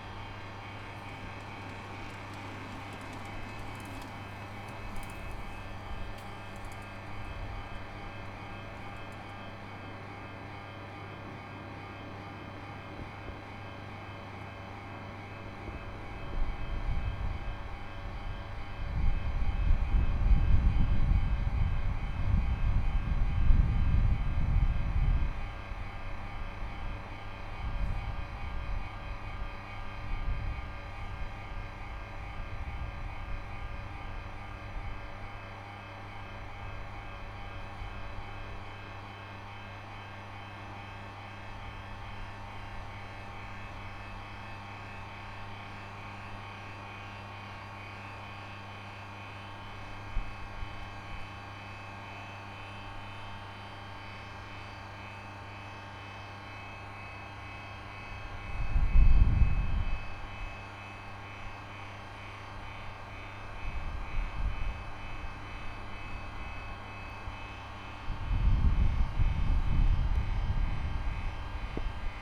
birmingham-erdington-canal-transformer-3.wav